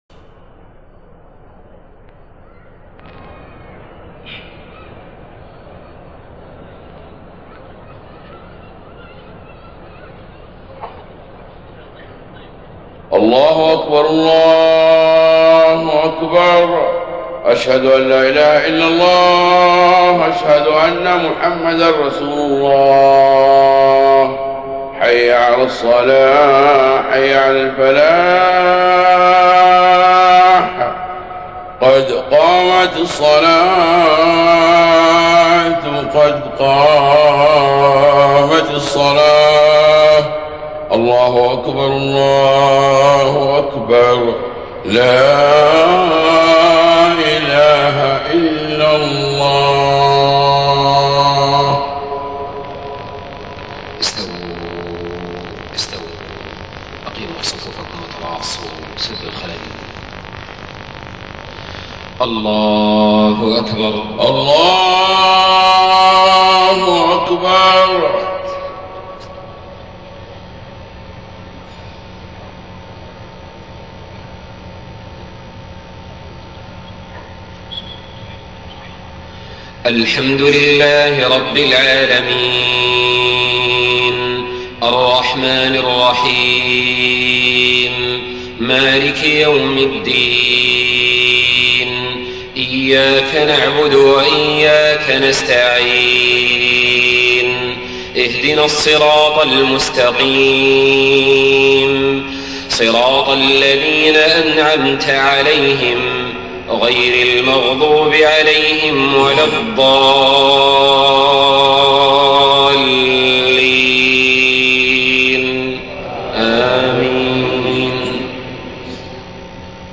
صلاة العشاء 16 محرم 1430هـ خواتيم سورة هود 110-123 > 1430 🕋 > الفروض - تلاوات الحرمين